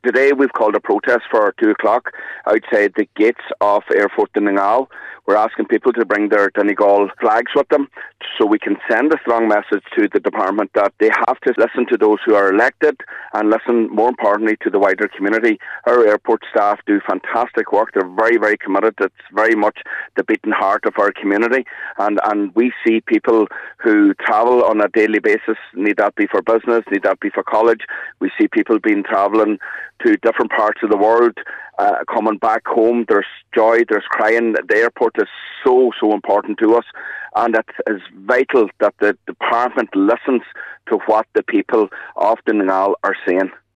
The demonstration is being led by local councillor Michael Cholm McGiolla Easbuig, who says the Public Service Obligation contract is there to serve the community, and the community’s voice must be listened to…………